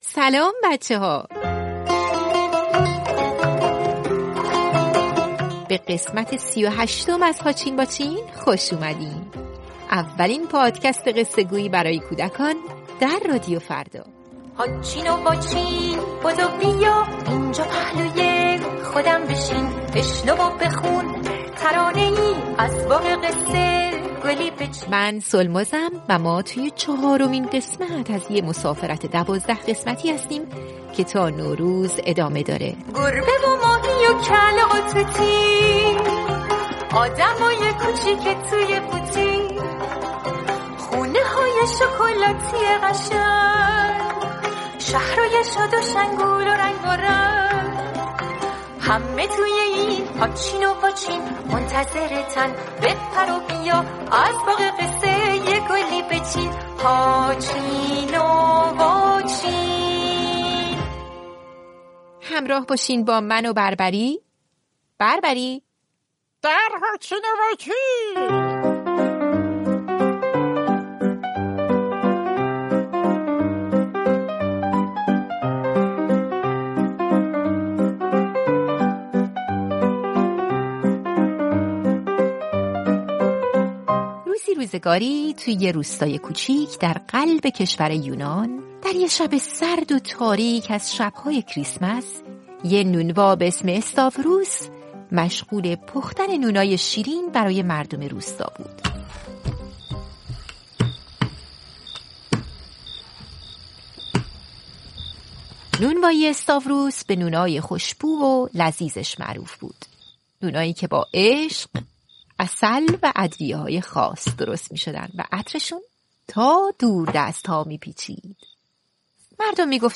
کتاب صوتی
مجموعه قصه‌های کودکان